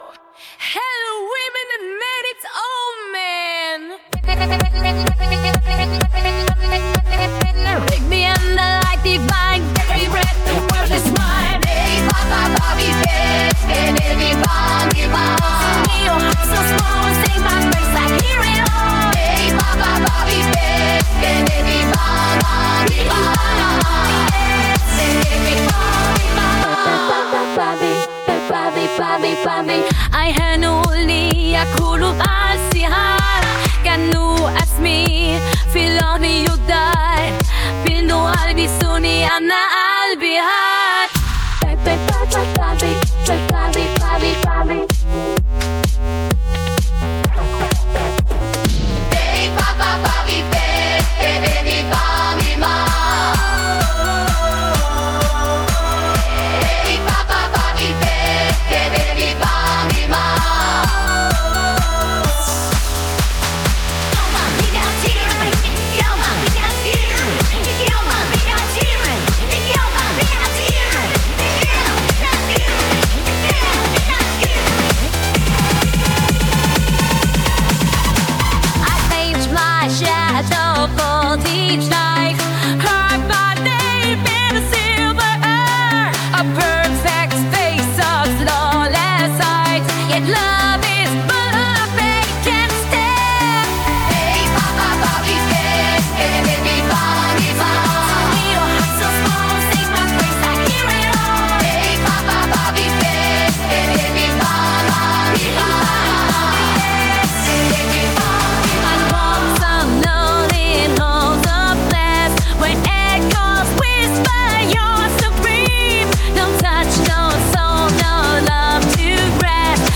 Muzyka: Udio